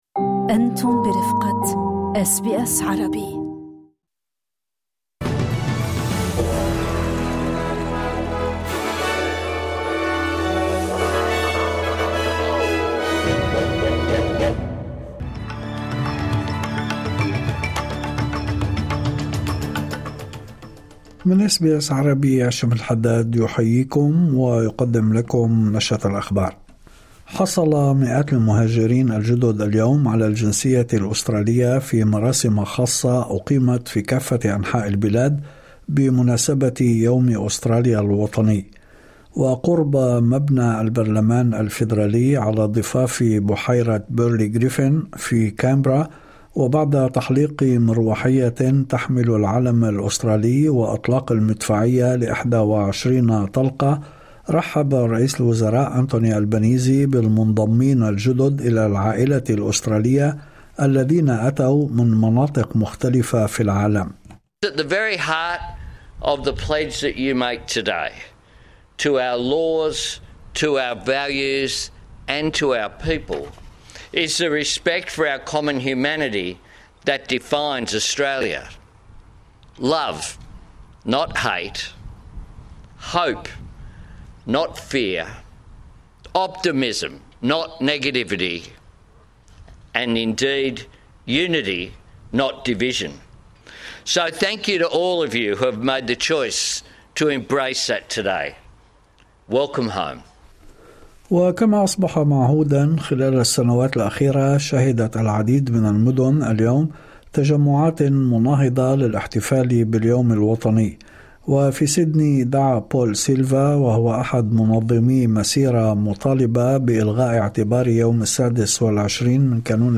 نشرة أخبار الظهيرة 26/1/2026